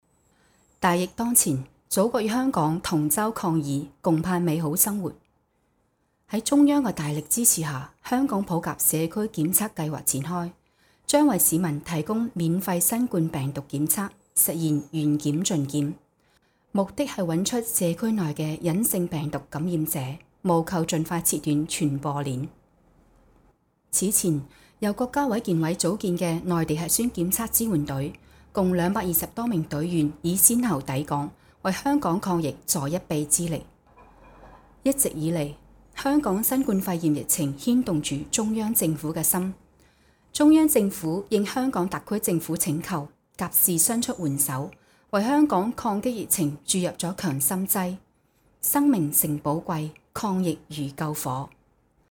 女声 Female Voice-公司名
女粤DY 粤语女声 粤语新闻